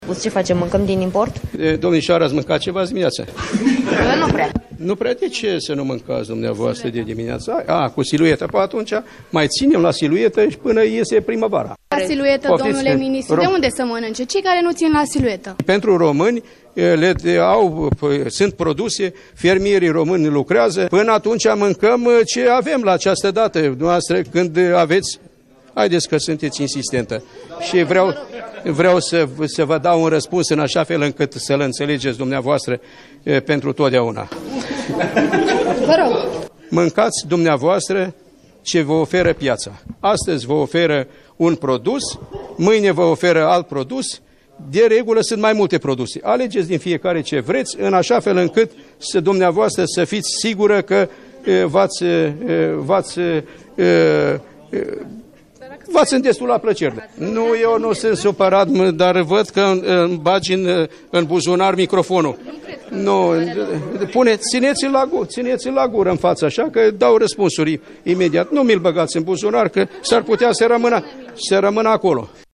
Legumele de primăvară, precum salata, ceapa verde, castraveţii şi roşiile, vor veni în acest an cu o uşoară întârziere, din cauza temperaturilor scăzute din ultima vreme, însă preţul acestora va fi mai mare doar la început, aşa cum se întâmplă în fiecare an până se reglează piaţa, a declarat, luni, ministrul de resort, Petre Daea, prezent la o conferinţă pe teme de profil.
O jurnalistă l-a întrebat pe ministru dacă românii vor mânca alimente din import până apar trufandalele autohtone.